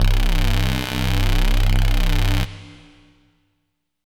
bass note.wav